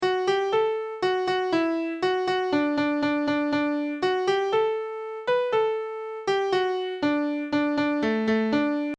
All sing as they play.